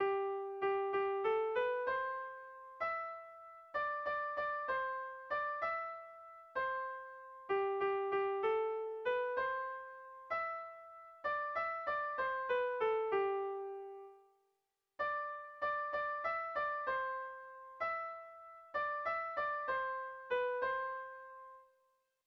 Seiko txikia (hg) / Hiru puntuko txikia (ip)
A1A2A3